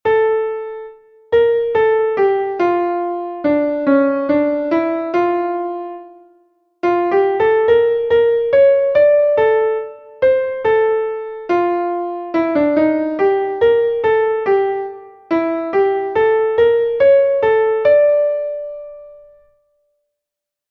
Entoación a capella
entonacionesacapelaud9.2.mp3